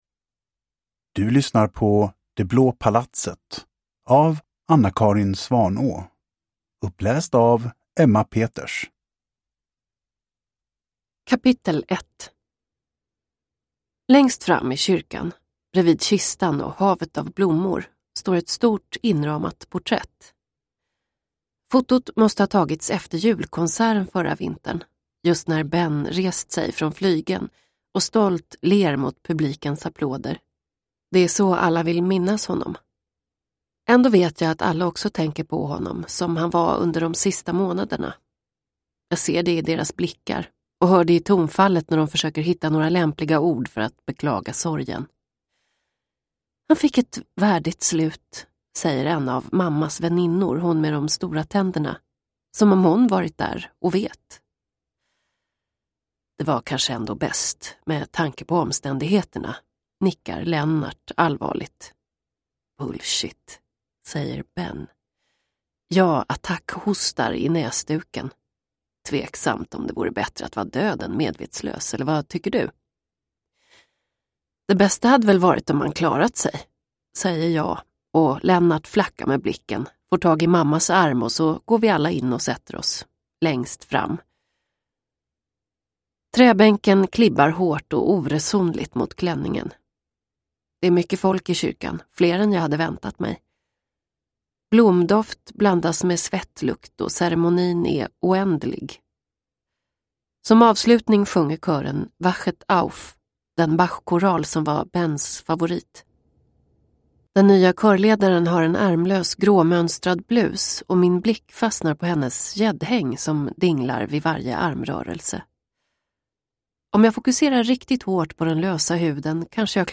Det blå palatset – Ljudbok – Laddas ner